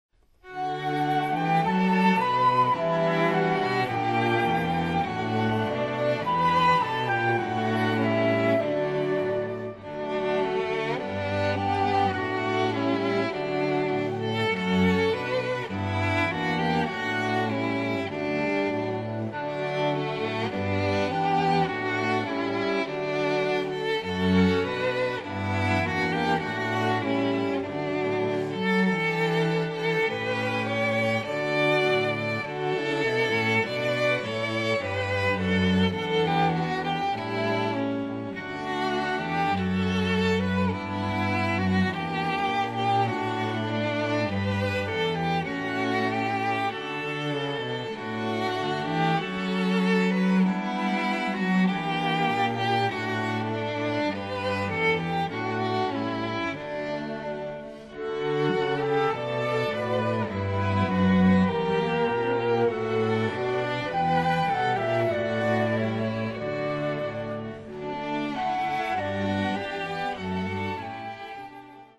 (Flute, Violin, Viola and Cello)
MIDI